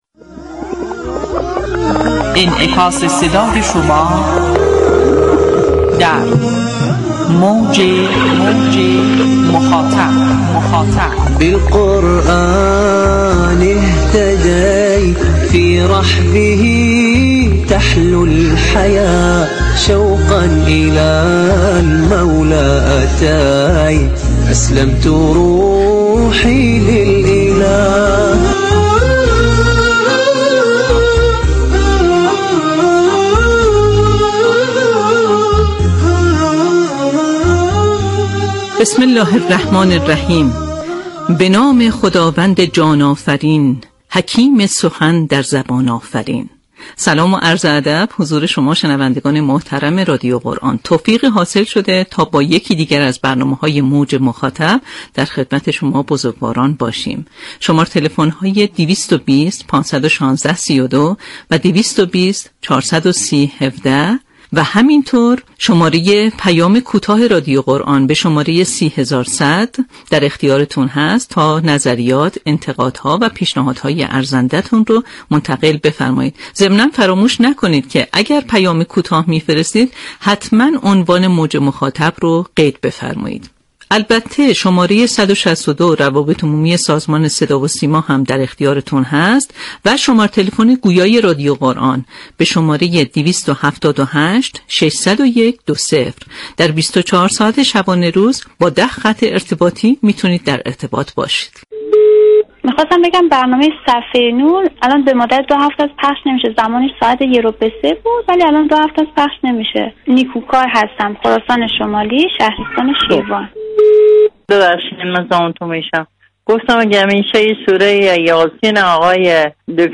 معرفی برنامه‌های رادیو قرآن اعم از برنامه‌ های زنده و تولیدی، پاسخگویی مطالبات مخاطبان رادیو قرآن (از طریق راه‌های ارتباطی) و همچنین، پخش تلاوت‌های ناب هدف اصلی این برنامه است.
همچنین گفت‌وگو با مدیران گروه‌های برنامه‌ساز و اطلاع‌رسانی برنامه‌های جدید در ایام و مناسبت‌های مختلف، ارتباط با گزارشگران مراكز شهرستان‌ها و اطلاع از برنامه‌های مراكز مختلف از دیگر بخش‌های برنامه خواهد بود.